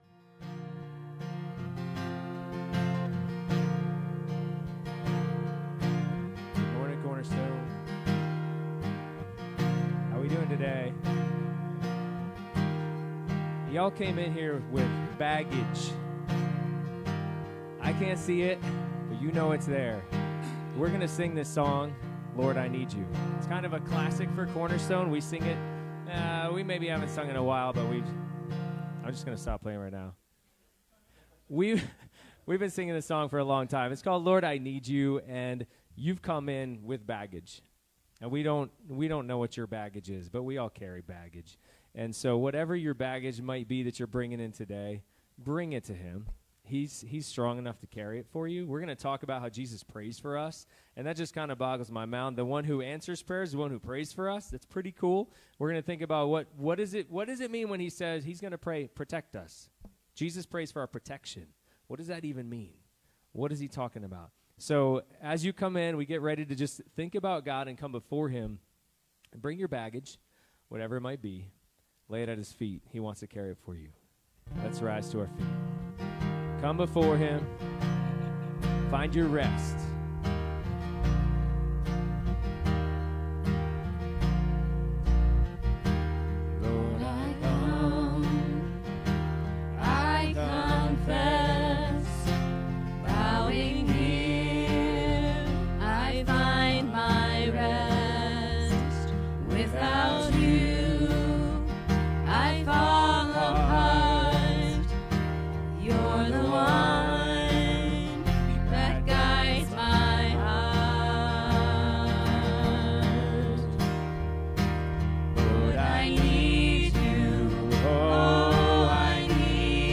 John 17:6-19 Service Type: Sunday Morning Youversion Event Bulletin PDF What kind of protection do I need MOST?